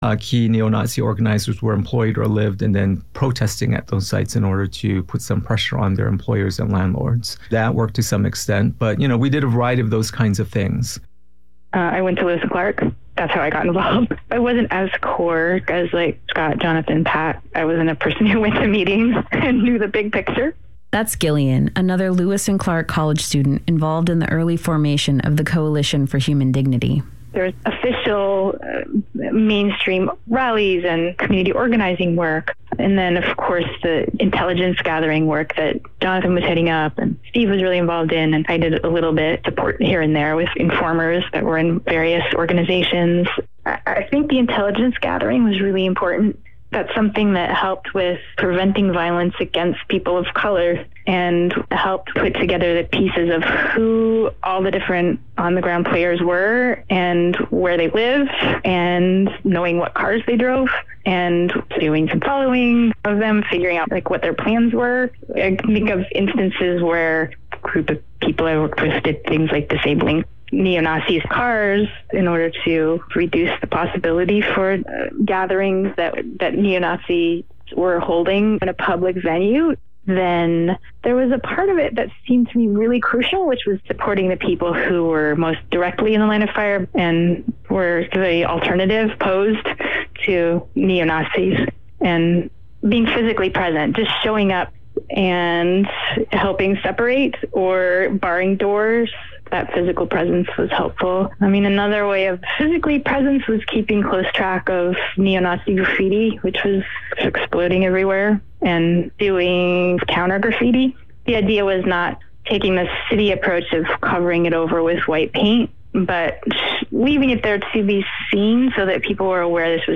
Download audio file Decolonize, with the best in Indigenous music, commentary and culture . The best in native Rap and R&B, plus political analysis.